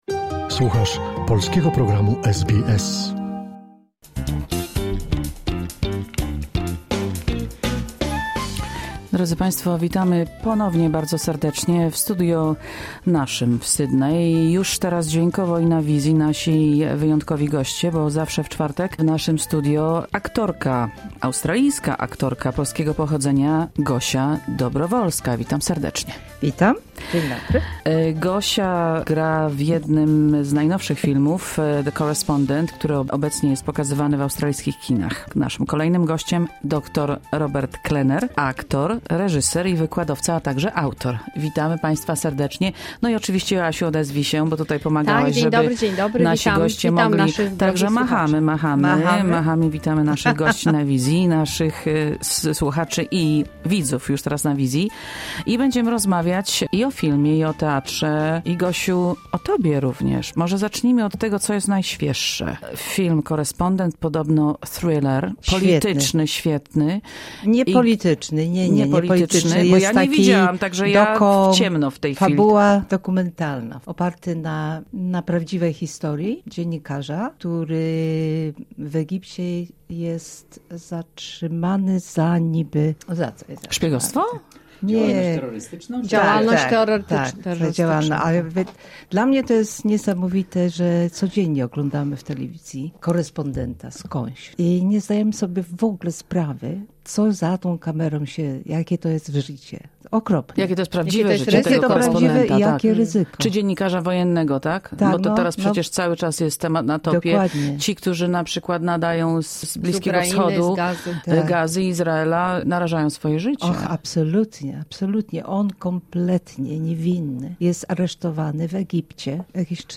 gośćmi w studio radia SBS